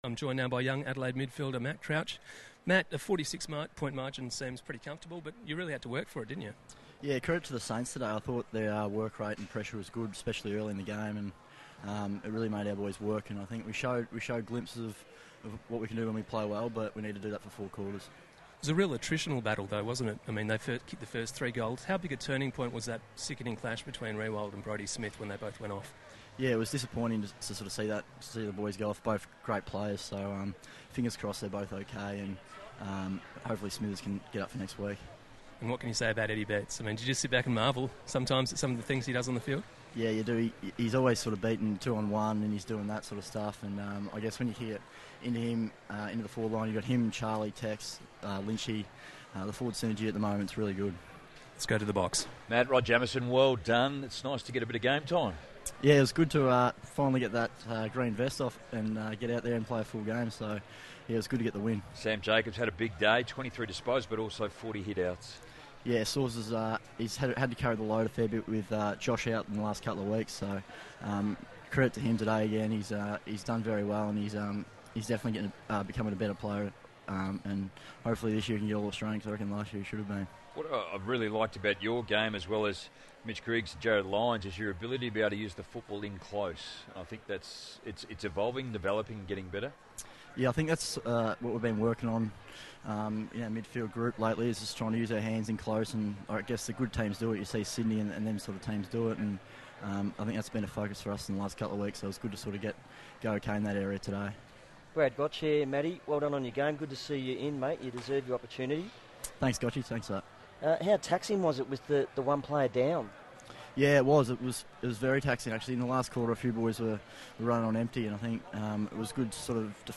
Matt Crouch on ABC Radio